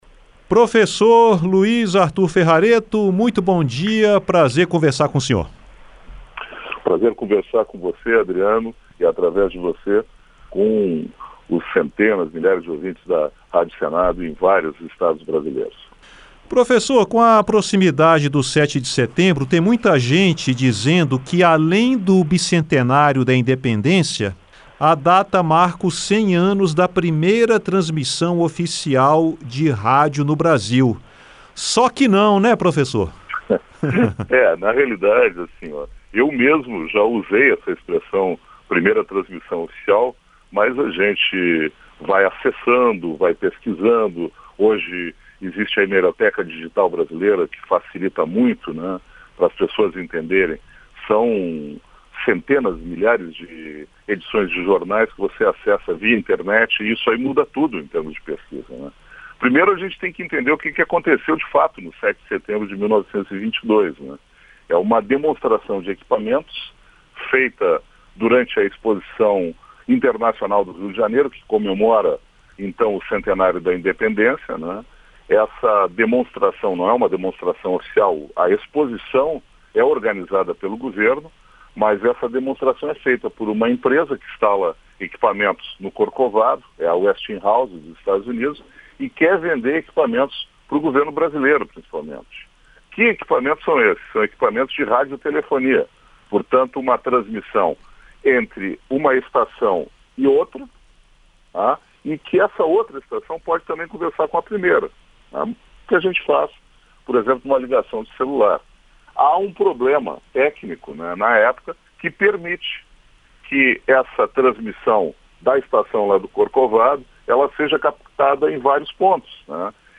Entrevista Originalmente veiculada em 05/09/2022.